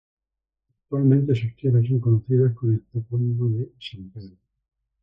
Pronounced as (IPA) /ˈpedɾo/